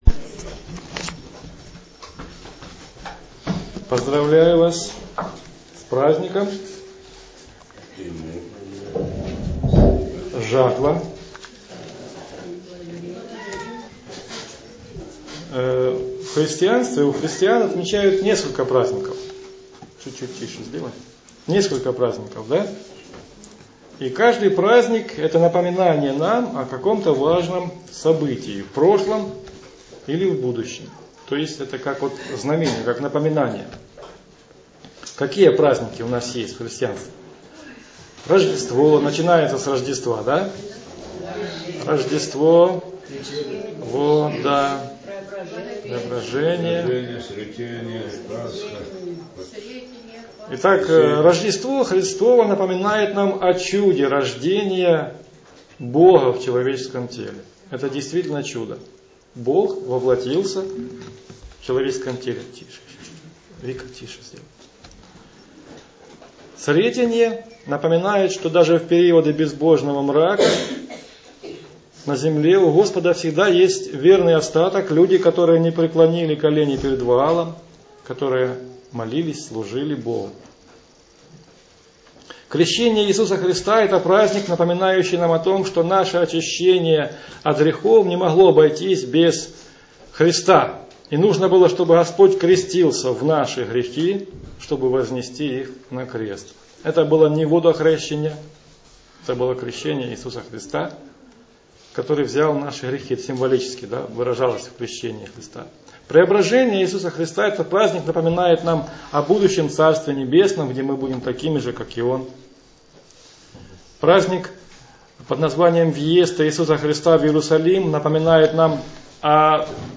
Сегодня в нашей Церкви мы отмечали праздник "Жатва".
Аудио-проповедь